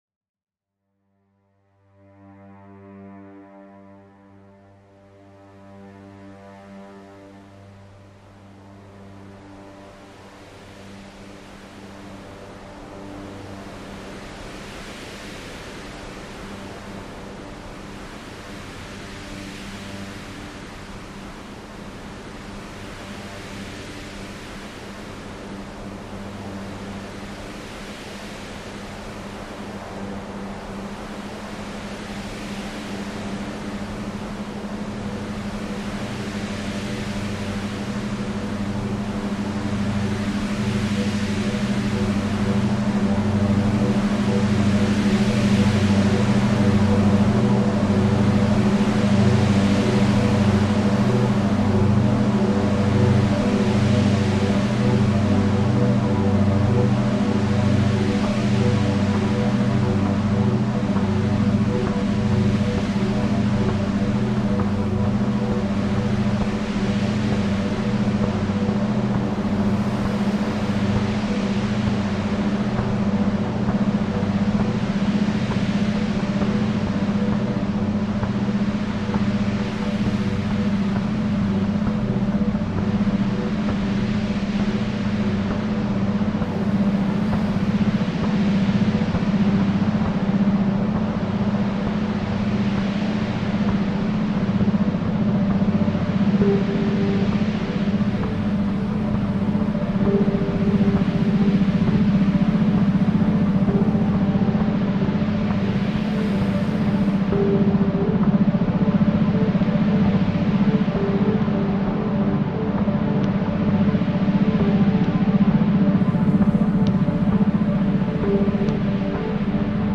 entwickelt, nutzt dieser Track binaurale Beats im Beta-Bereich
sowie strukturiertes Noise-Layering, um deine Aufmerksamkeit zu